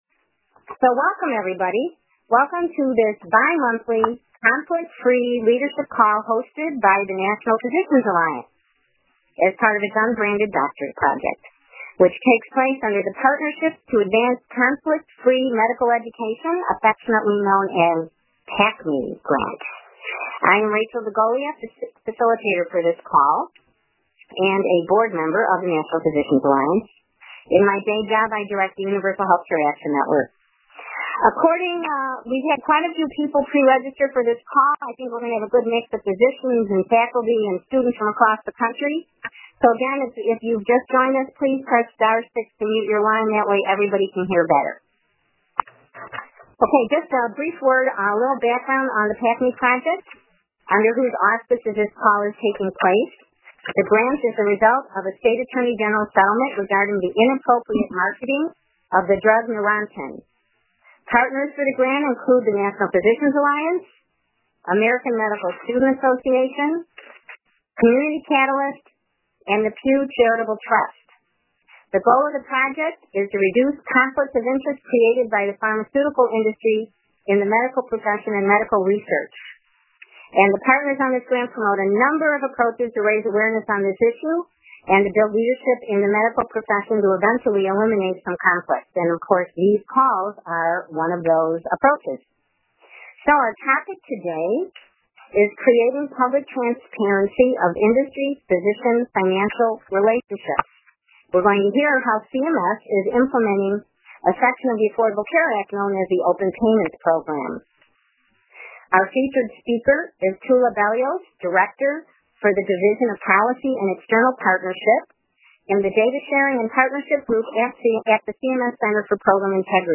This lecture was recorded on April 30, 2014.